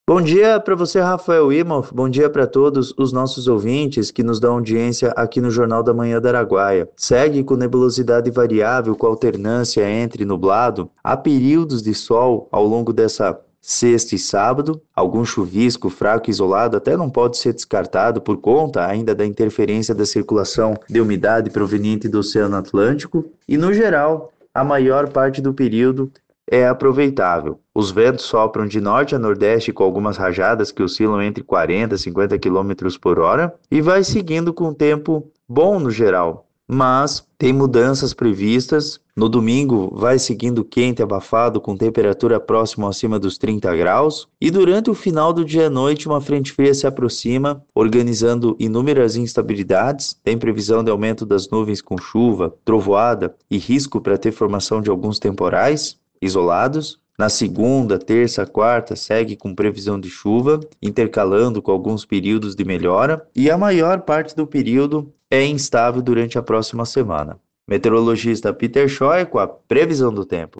Ao microfone da Rádio Araguaia, o vereador eleito Pedro destacou o que a população de Brusque pode aguardar seu mandato: